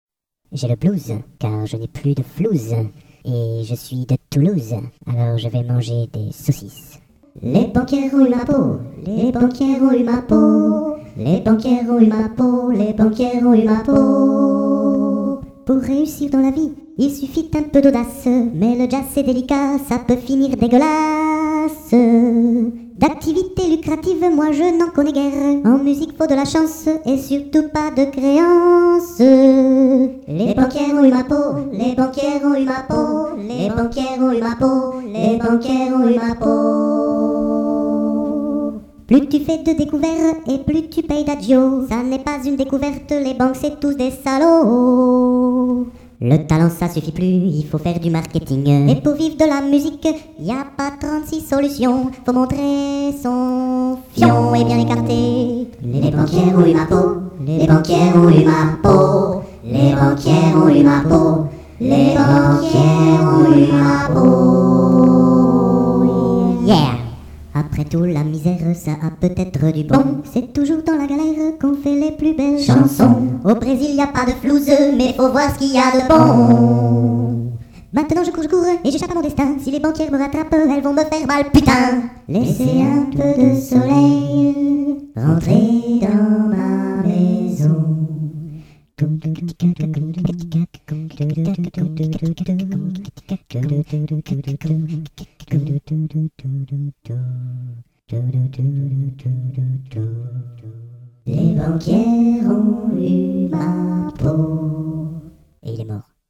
Blues acapella sur les déboires financiers. 2002